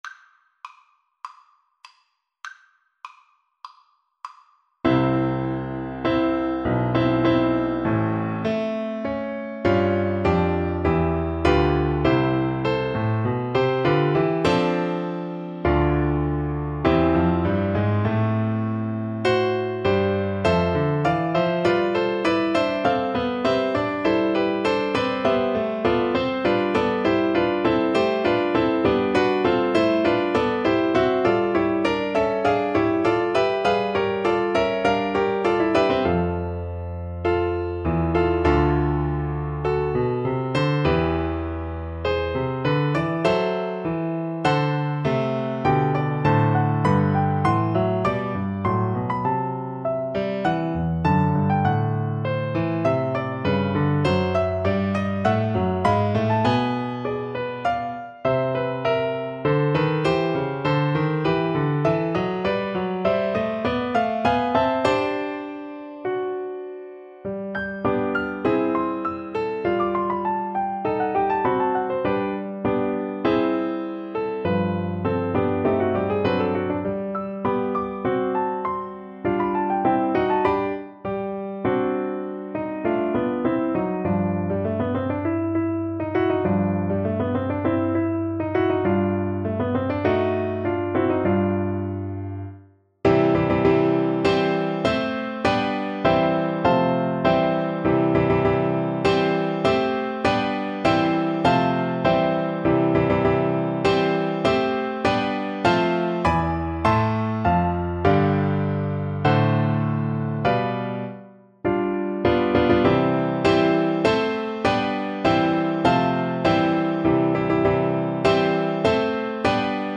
Play (or use space bar on your keyboard) Pause Music Playalong - Piano Accompaniment Playalong Band Accompaniment not yet available reset tempo print settings full screen
C major (Sounding Pitch) G major (French Horn in F) (View more C major Music for French Horn )
Molto moderato mosso
4/4 (View more 4/4 Music)